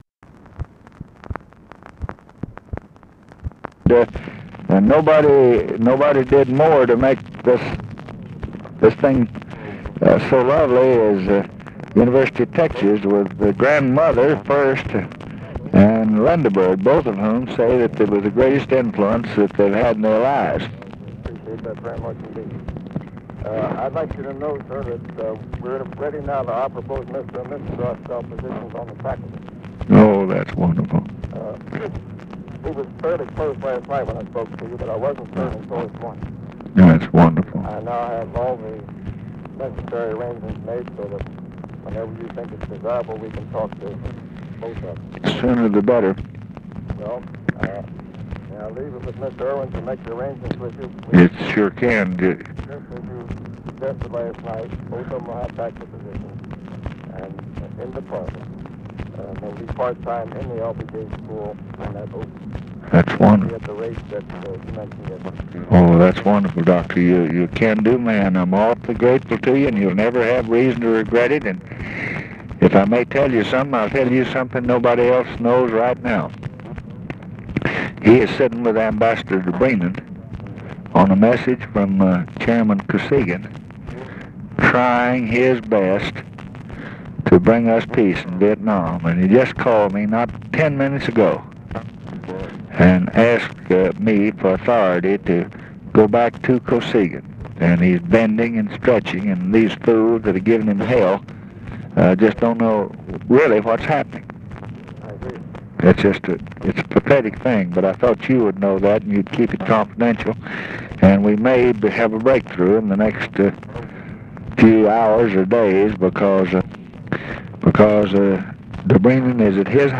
Conversation with FRANK ERWIN, October 26, 1968
Secret White House Tapes